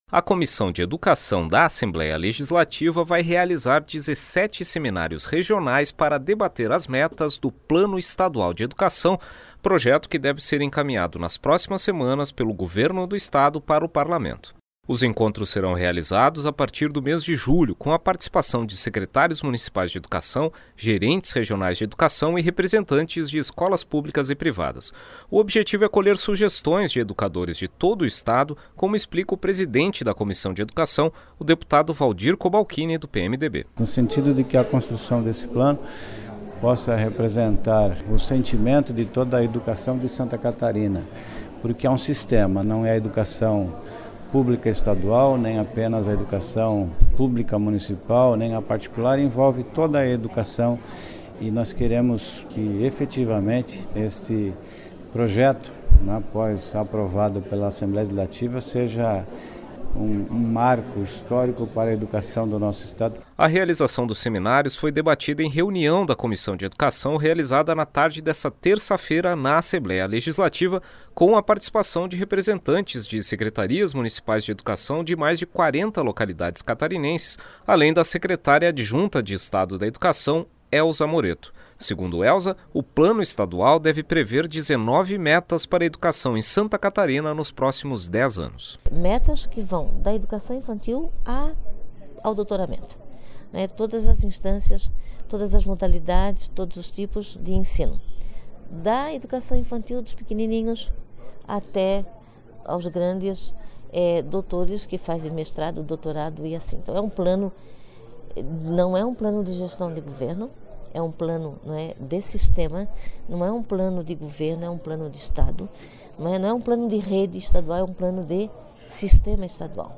Entrevistas com: Elza Moretto, secretária adjunta de Estado de Educação; deputado Valdir Cobalchini (PMDB), presidente da Comissão de Educação da Assembleia Legislativa.